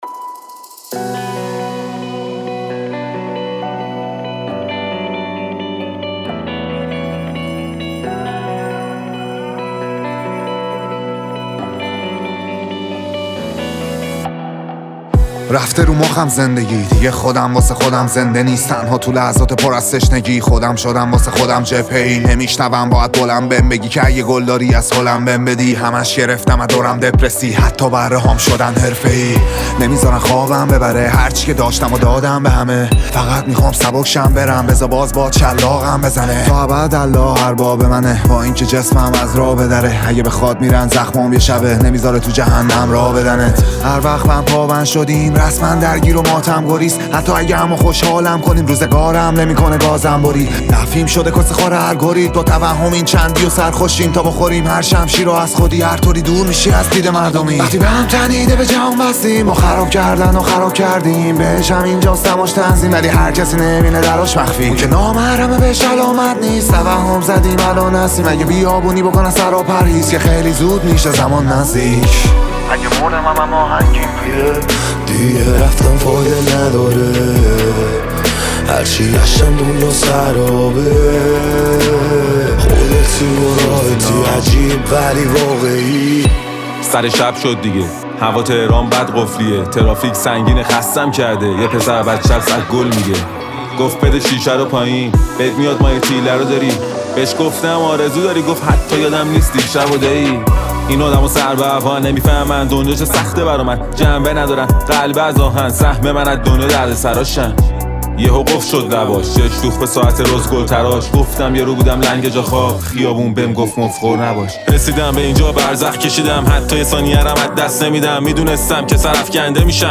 شاد و پر انرژی